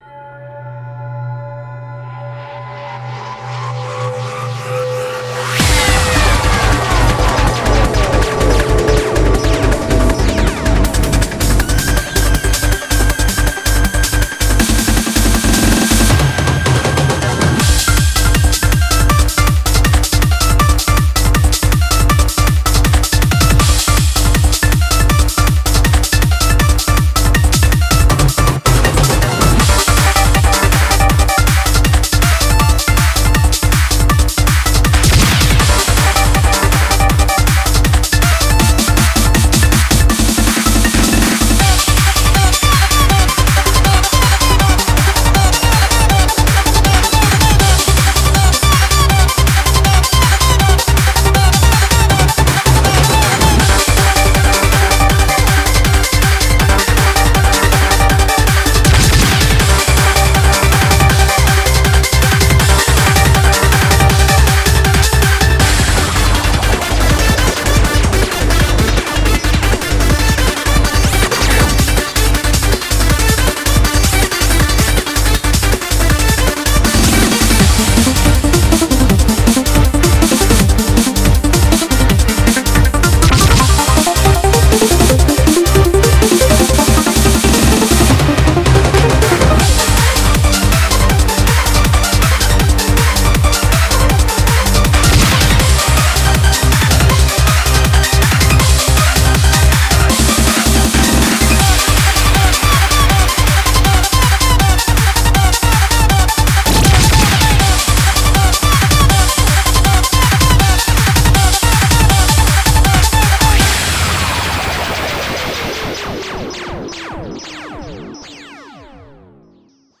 BPM160
Audio QualityPerfect (High Quality)
CommentairesHARD STACC